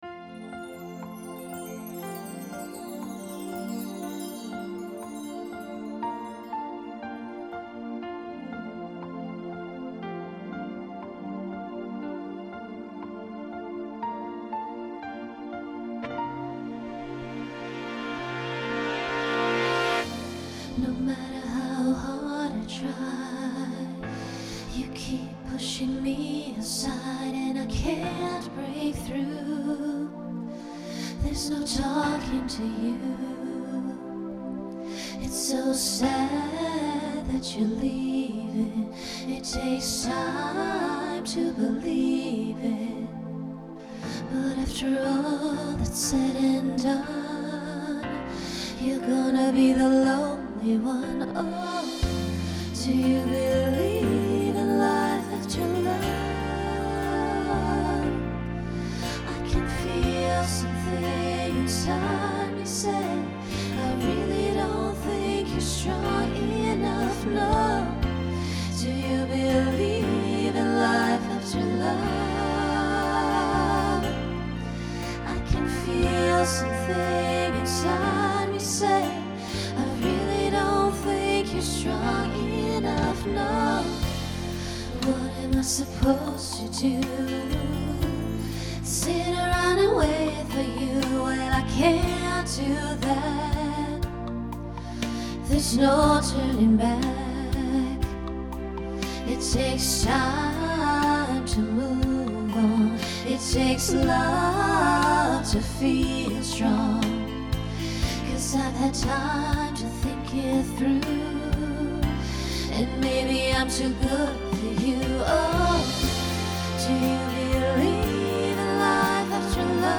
Genre Pop/Dance
Function Ballad Voicing SSA